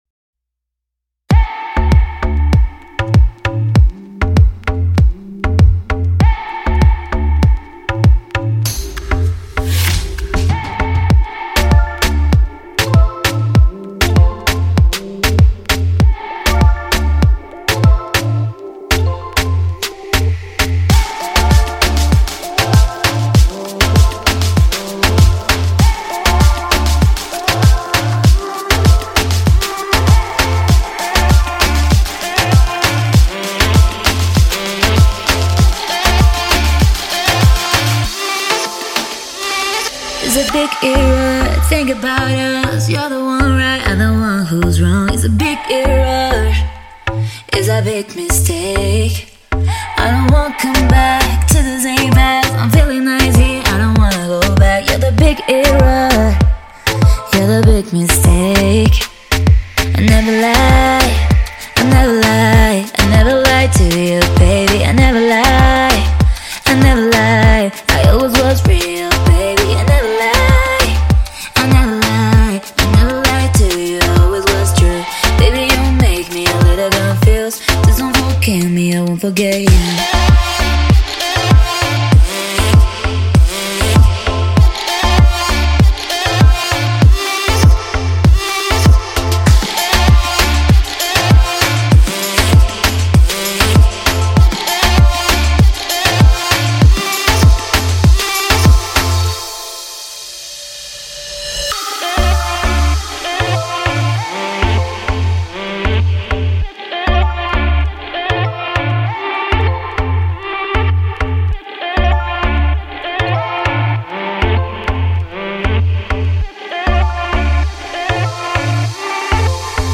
это зажигательный трек в жанре электронного дэнс-музыки
Энергичное исполнение